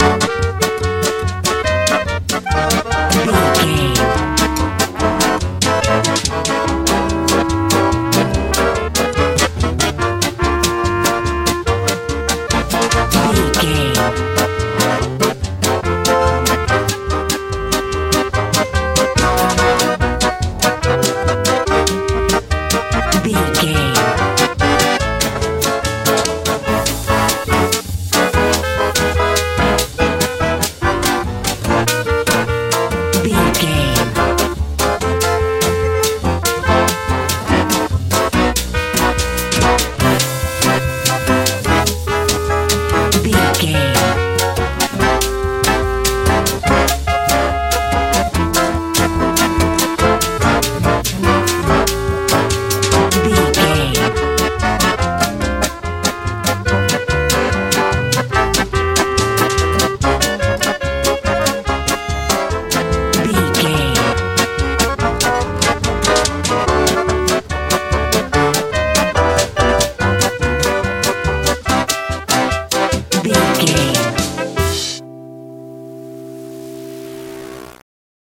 Mixolydian
D
fun
accordion
horns
acoustic guitar
bass guitar
drums
tension
soothing
mystical
groovy
funny